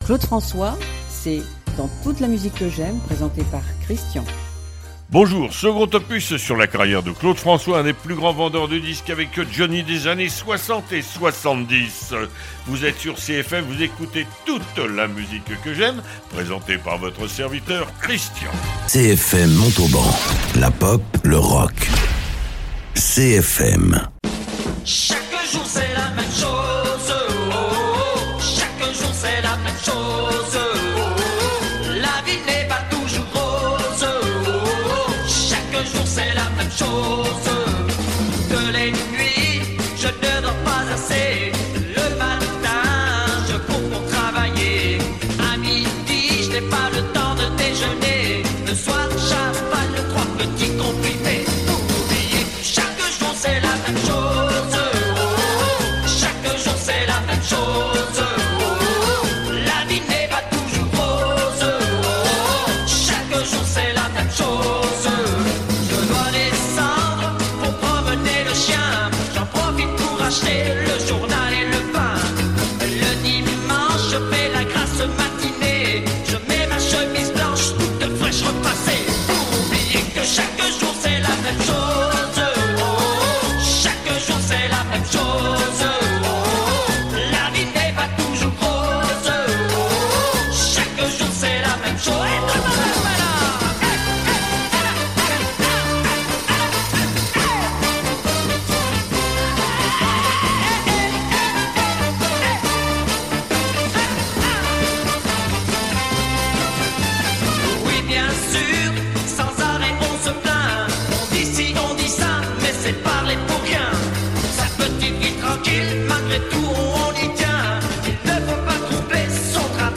Invité(s) : Claude François , ses intervews clash